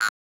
acid_outro_088.ogg